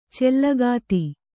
♪ cellagāti